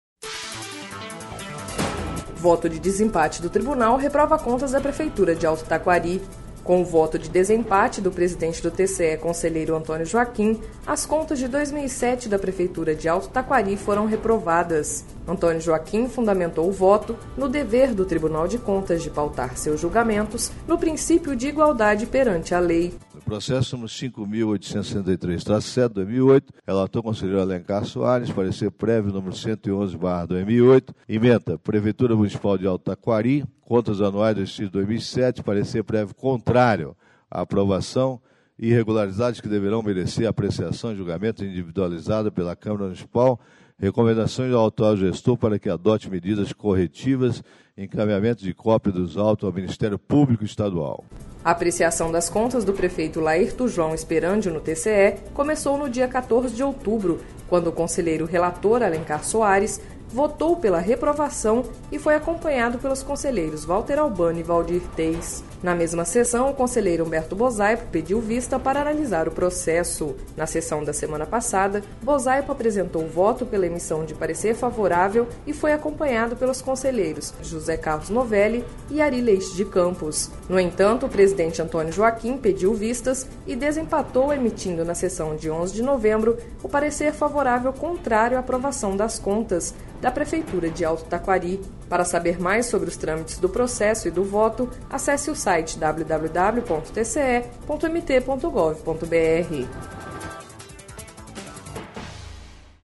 Sonora: Antonio Joaquim – conselheiro presidente do TCE-MT